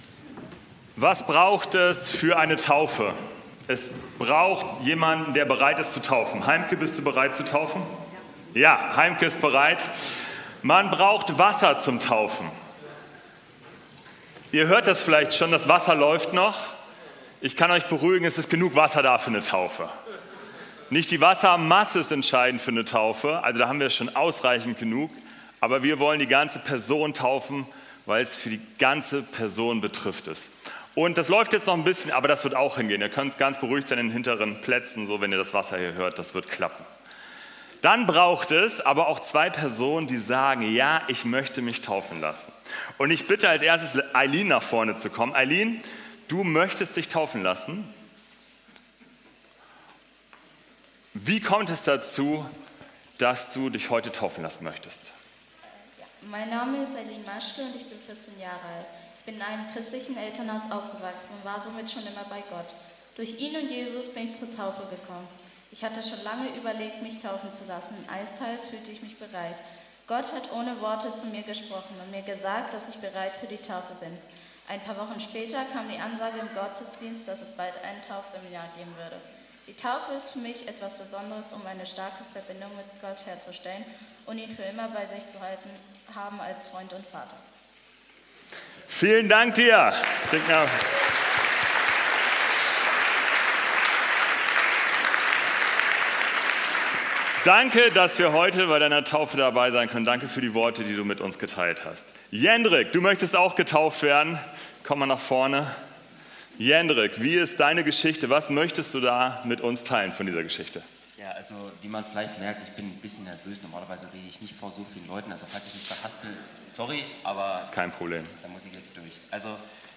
Taufgottesdienst